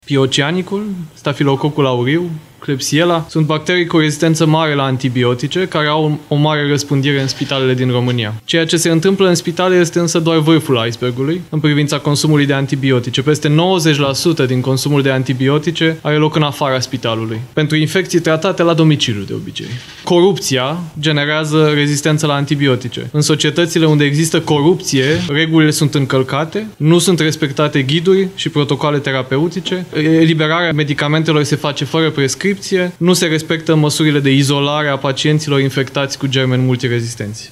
Până în 2050, în toată lumea, se vor înregistra 10 milioane de decese produse de infecții cu germeni rezistenți la antibiotice – a spus ministrul Sănătății, Vlad Voiculescu.